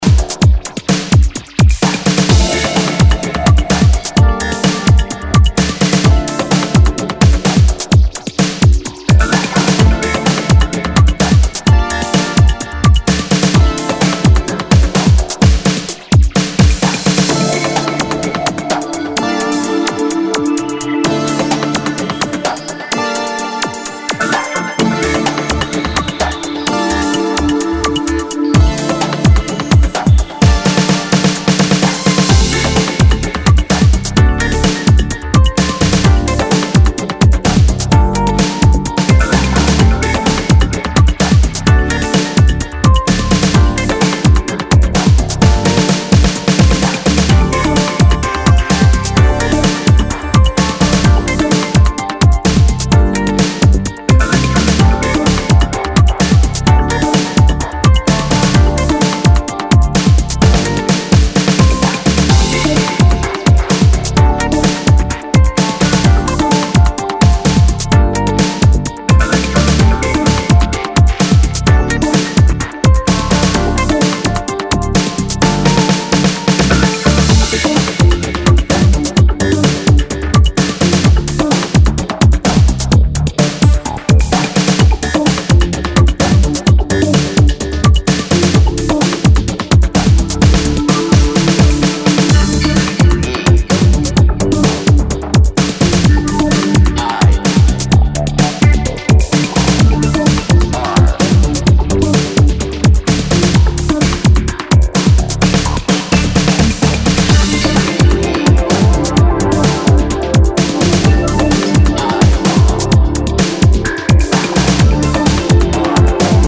a wonderful, floaty downtempo track